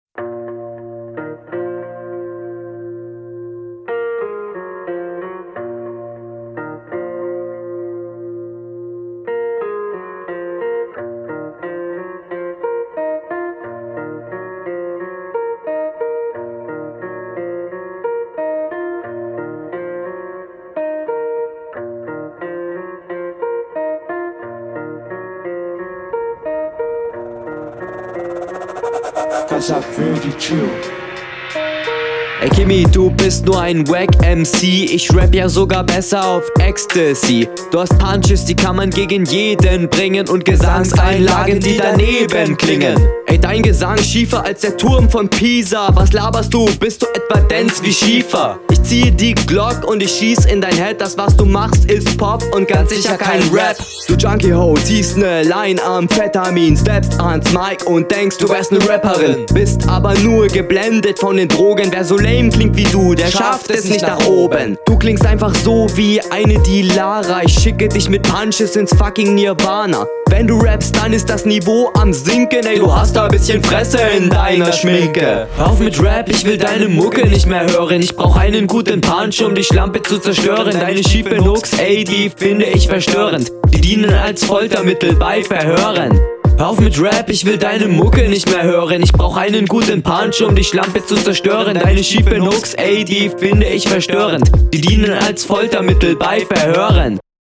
Flow: Klingt etwas abgehackt und noch nicht 100% souverän.
Cooler beat, wie heisst der?